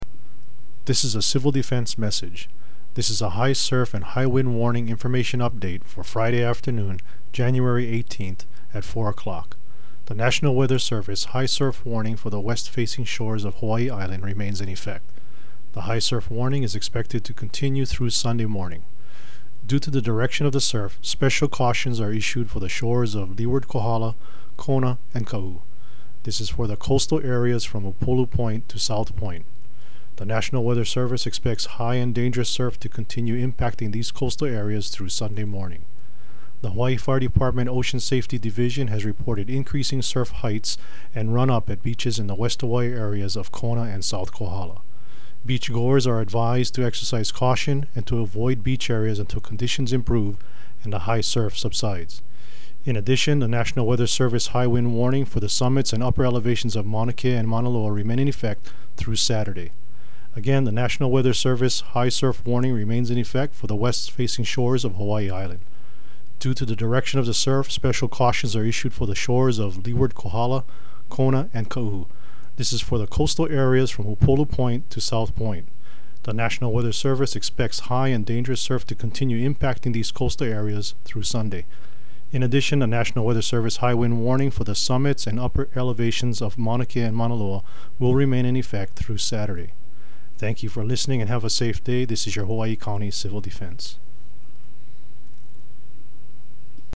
Hawaii County Civil Defense Audio Message